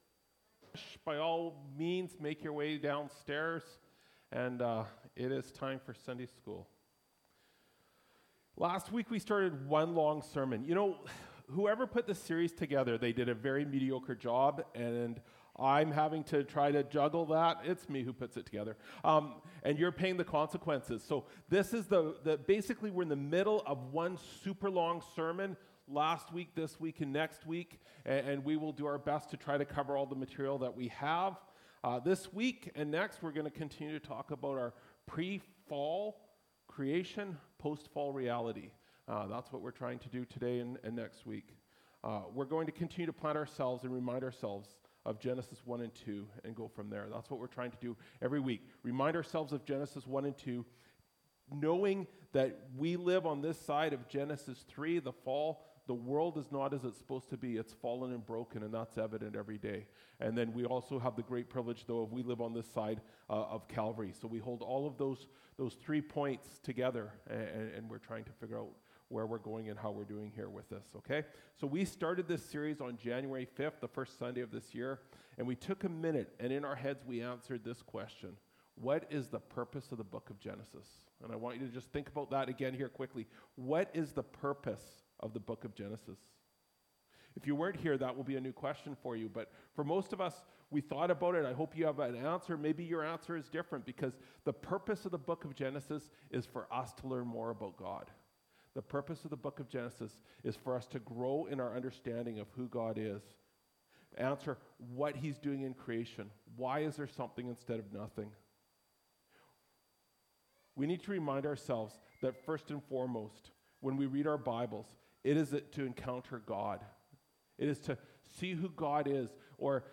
Feb 02, 2025 Pre-Fall Creation, Post-Fall Reality – Part 2 (Genesis 2) MP3 SUBSCRIBE on iTunes(Podcast) Notes Discussion Sermons in this Series Loading Discusson...